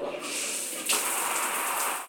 showerstart.ogg